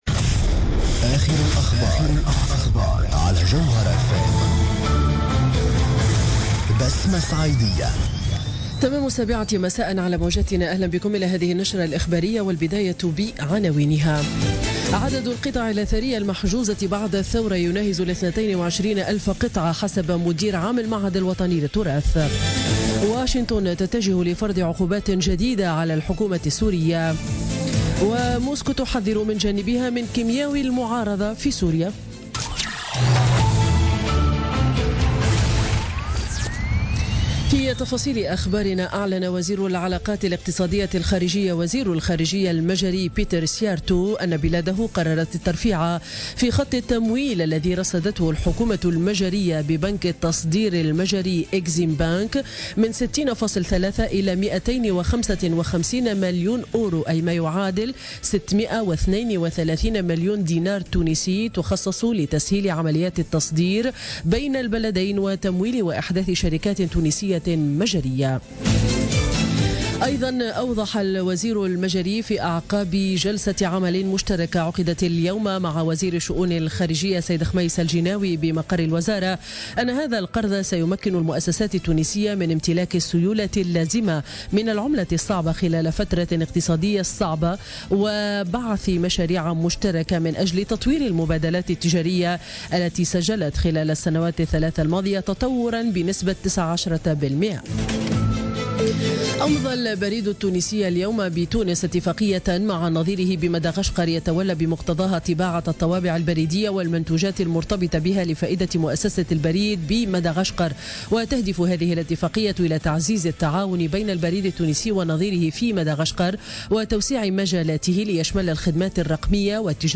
نشرة أخبار السابعة مساء ليوم الاثنين 24 أفريل 2017